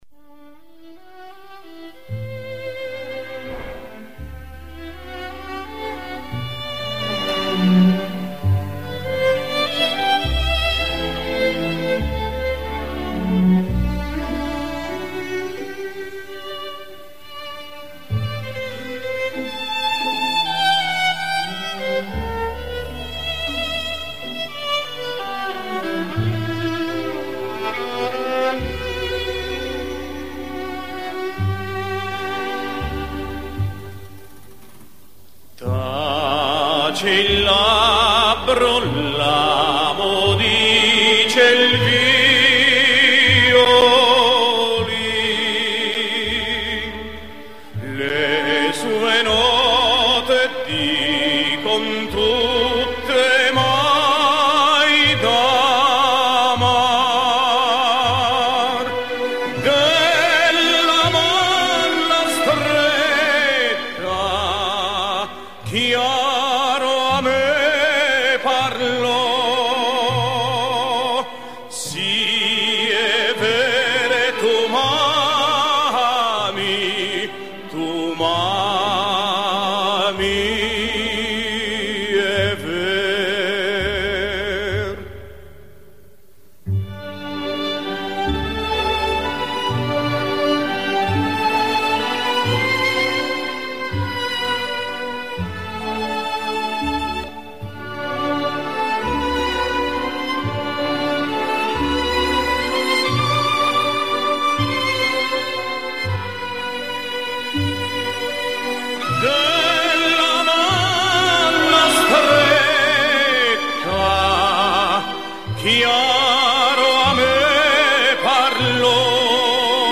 исполненных в духе любимых им неаполитанских песен …